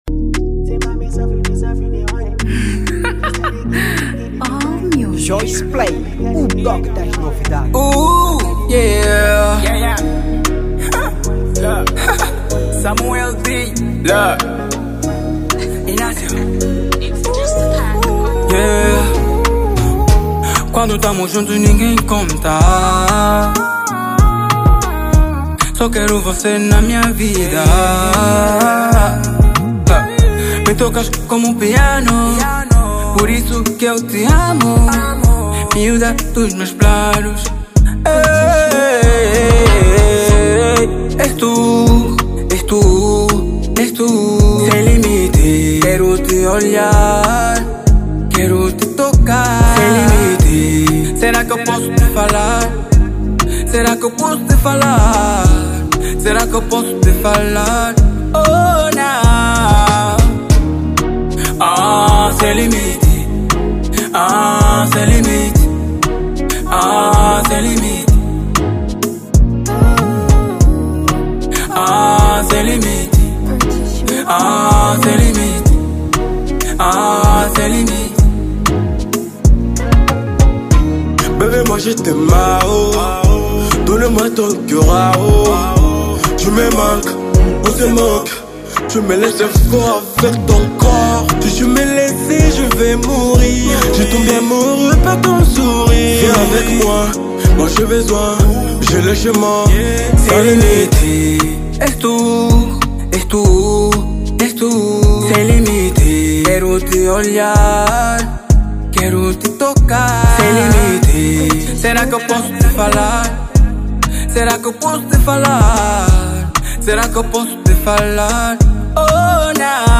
Afro Naija
Género: Afro Naija